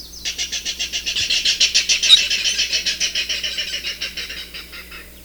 Clapper Rail
VOICE: The Clapper Rail makes a variety of calls including grunts, and a sound like clapping.
HABITAT: Mangroves and adjacent saltwater mudflats.